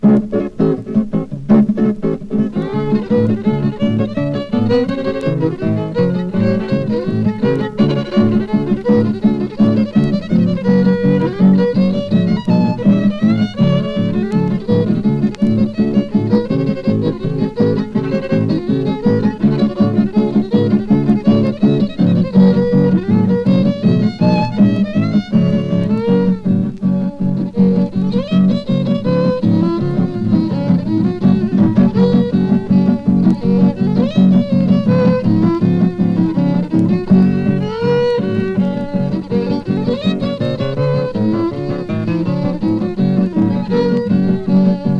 カントリーやウェスタンなそのものずばりの出立ちで、ギター奏者のカールとフィドル奏者のヒューの兄弟デュオ。
軽やか濃厚、驚きの連続が21曲もと大興奮。
※元音源に準ずるプチプチ音有り
Country, Western Swing, Jazz　Germany　12inchレコード　33rpm　Mono